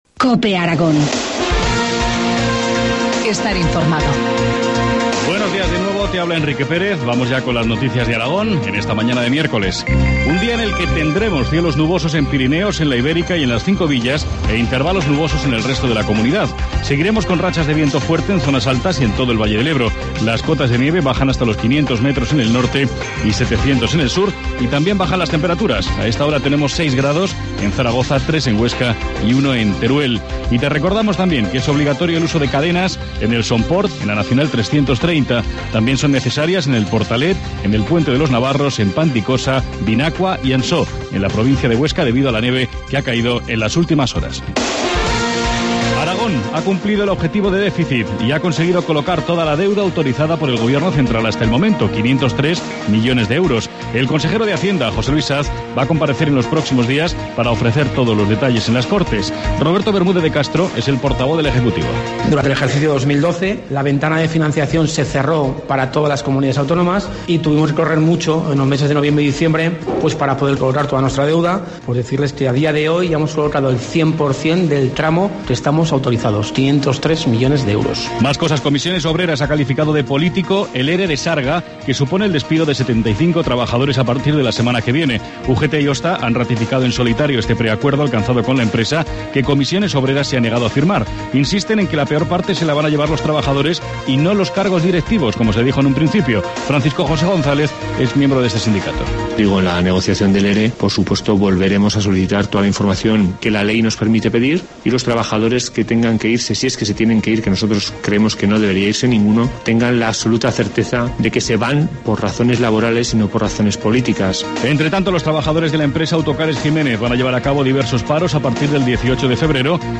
Informativo matinal, miércoles 6 de febrero, 8.25 horas